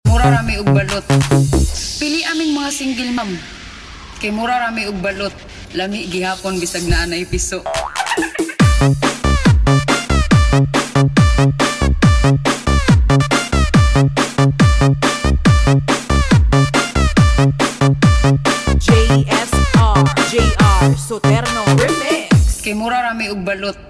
KAMAYADAN FESTIVAL 2025